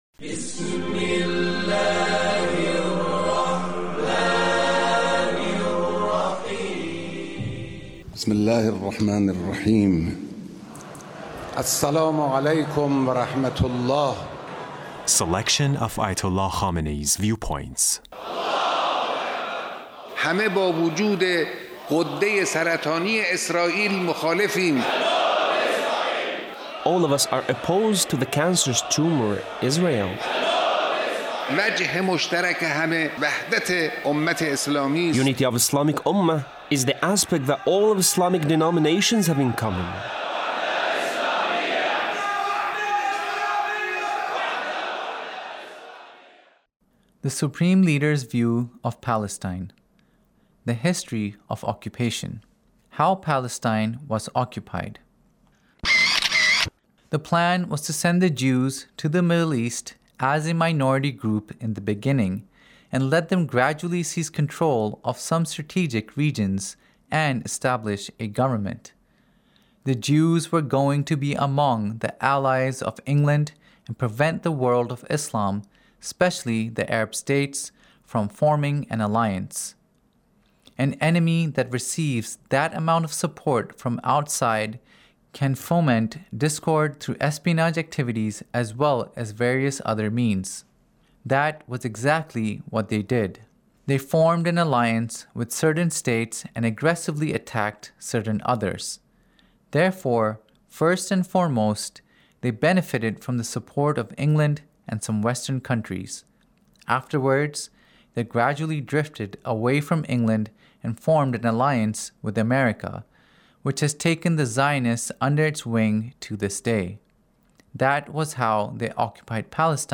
Leader's Speech (1872)
Leader's Speech in a Meeting with the Three Branches of Government Repentance